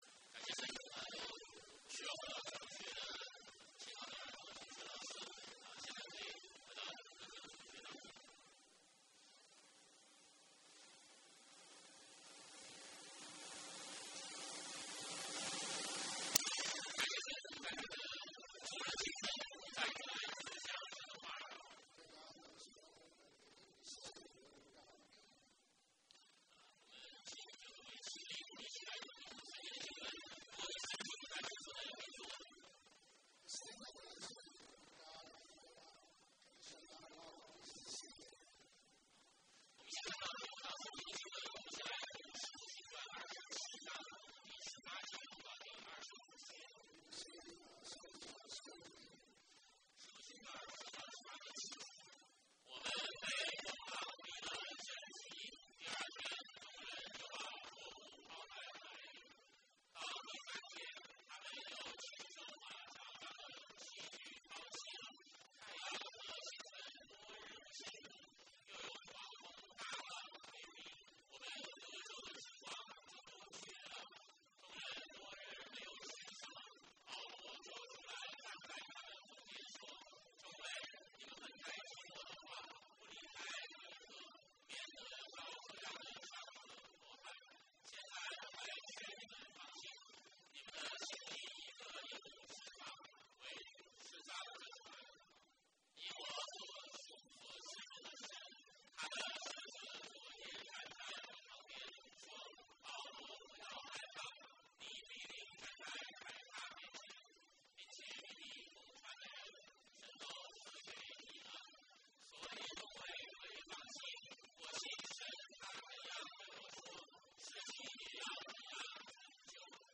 BCCC Sermon